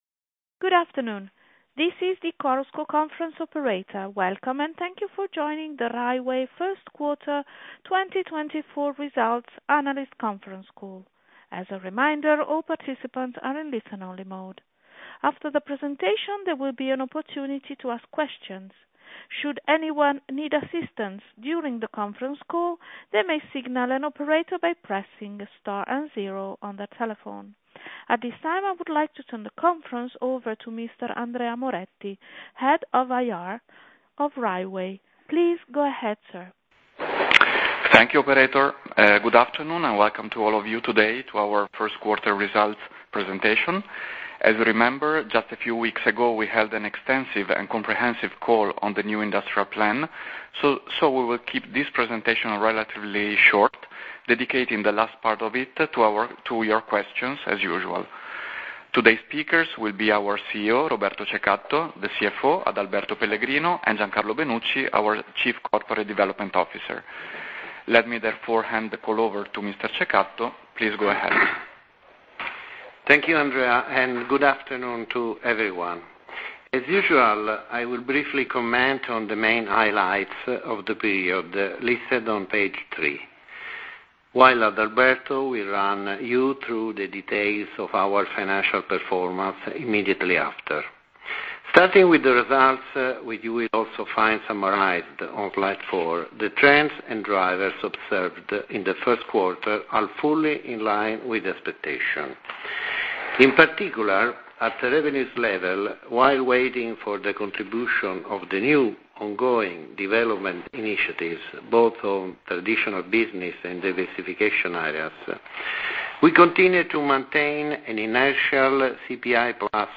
Audio mp3 Conference call Risultati 1Q2024.mp3